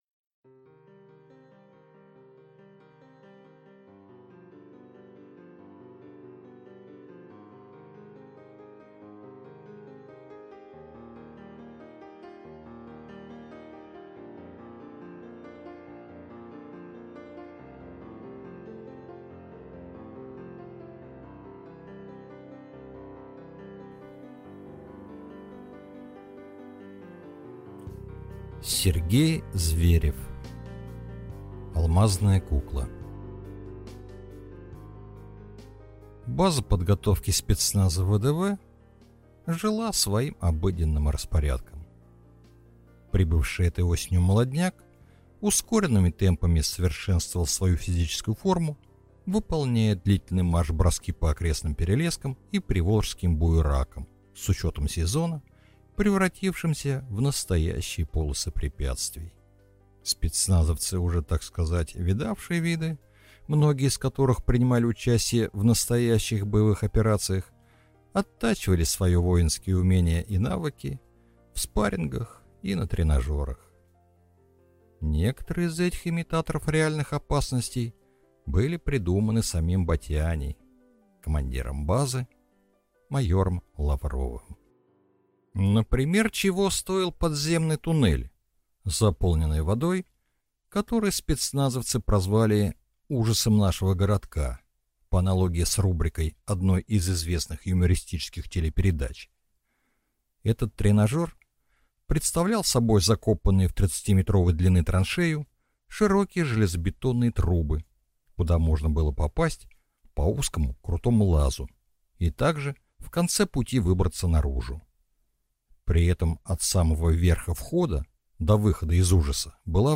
Аудиокнига Алмазная кукла | Библиотека аудиокниг
Прослушать и бесплатно скачать фрагмент аудиокниги